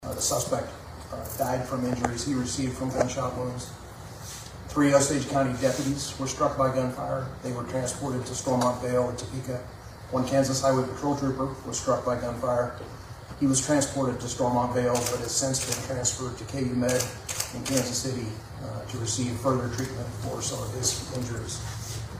KHP Colonel Erik Smith, in a press conference Saturday afternoon, stated that less than 10 minutes after authorities arrived on scene, “gunfire erupted.”